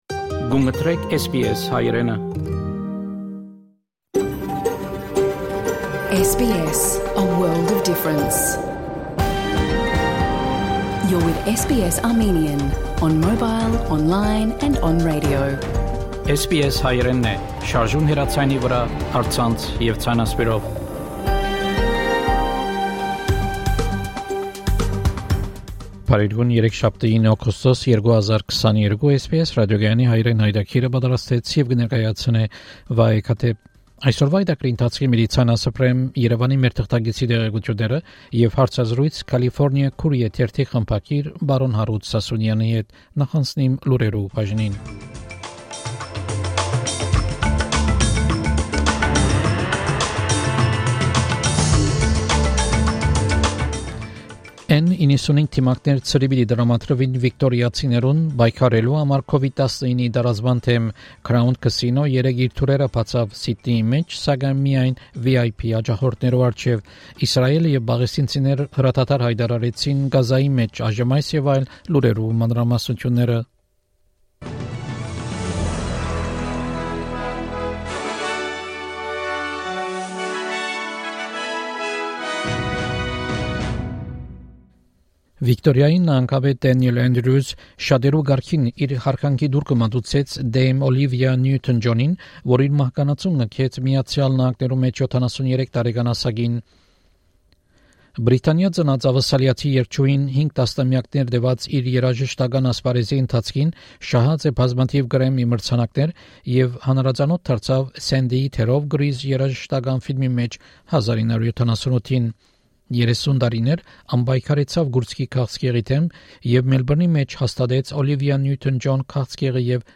SBS Armenian news bulletin – 9 August 2022
SBS Armenian news bulletin from 9 August 2022 program.